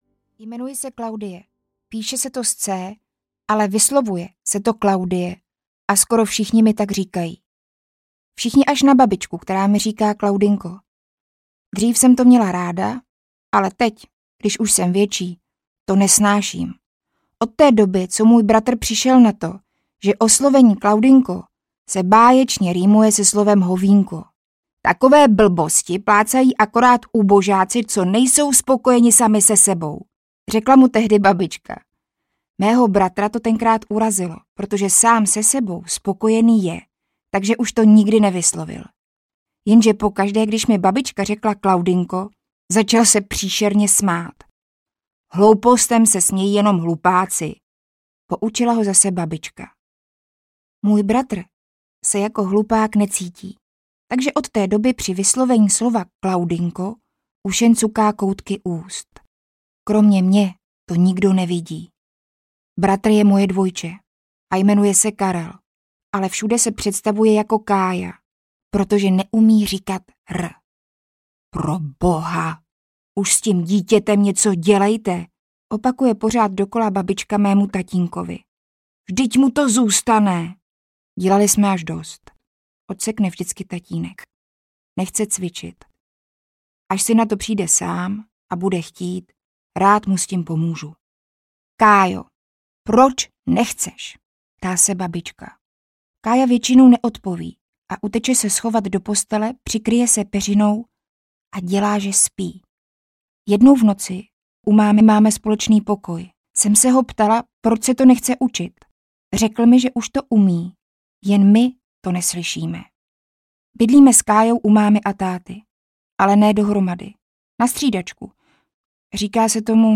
Kája a Claudie: Hra na štěstí audiokniha
Ukázka z knihy
• InterpretLinda Rybová, Lukáš Příkazký